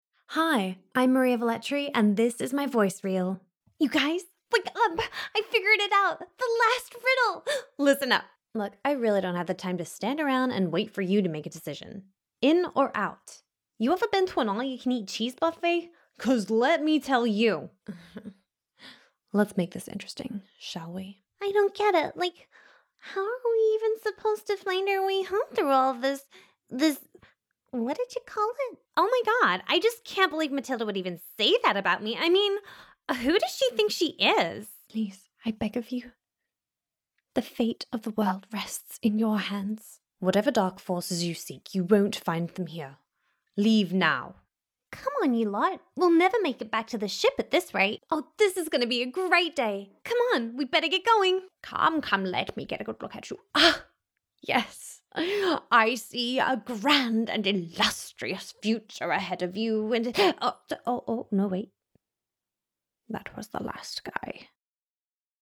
Character Voice Reel